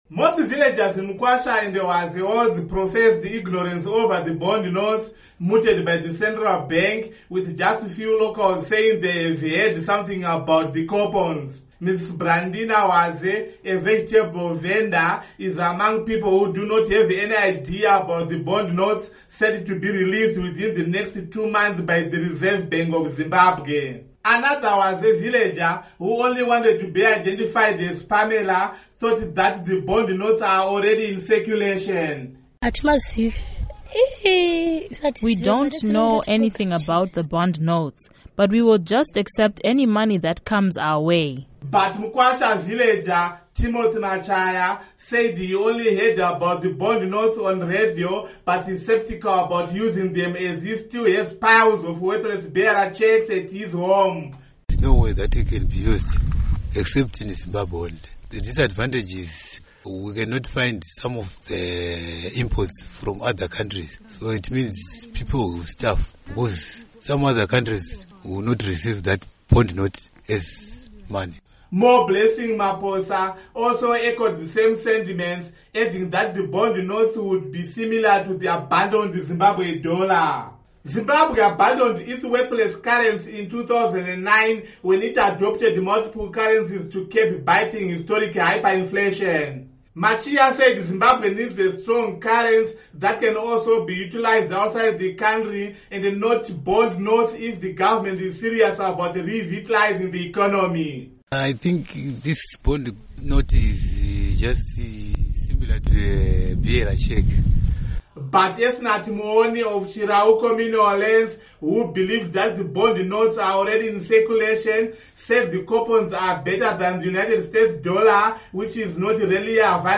Report on Economy, Bond Notes